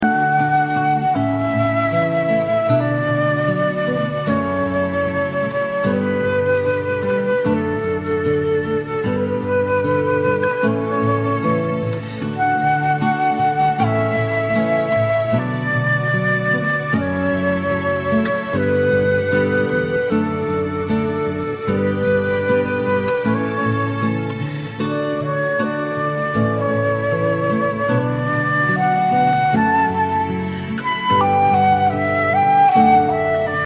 From classical flute to sophisticated jazz
Wedding Music Samples